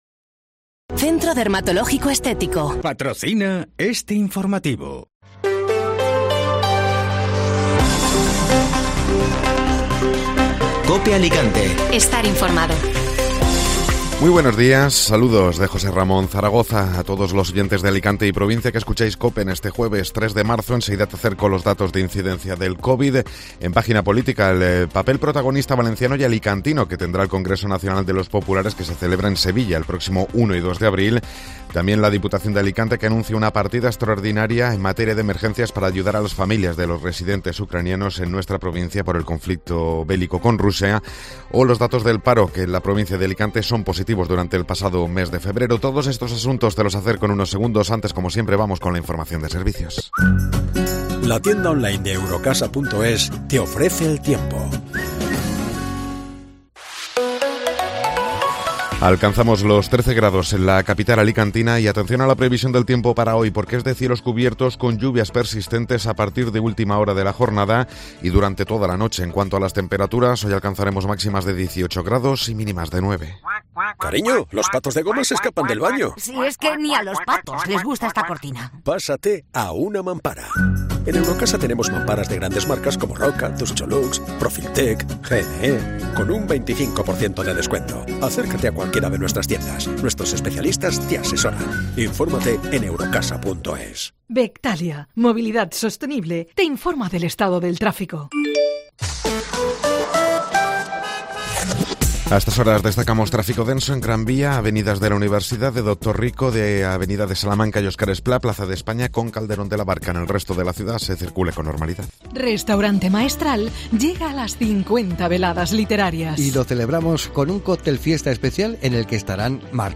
Informativo Matinal (Jueves 3 de Marzo)